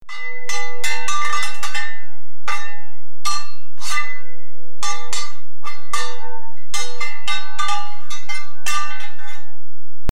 Sound: Swords Clash 1
DOWNLOAD: Two swords clash (WAV file)
48k 24bit Stereo
Try preview above (pink tone added for copyright).